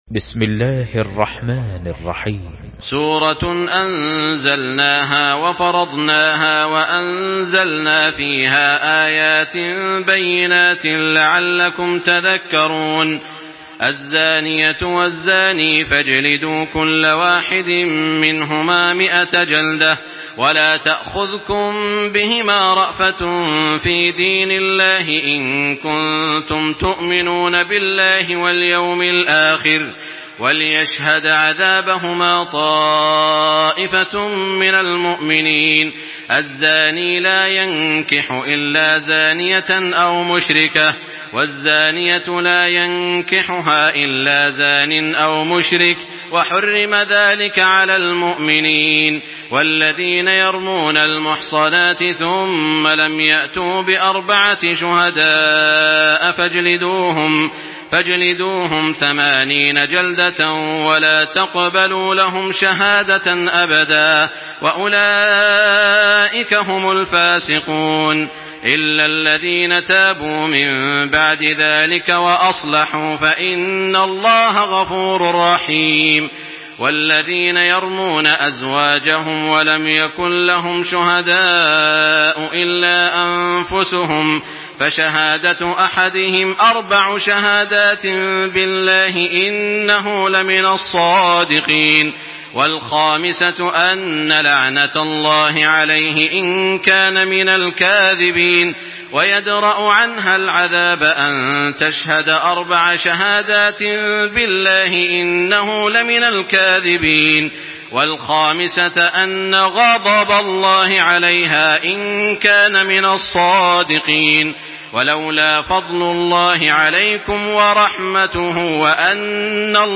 Surah Nur MP3 by Makkah Taraweeh 1425 in Hafs An Asim narration.
Murattal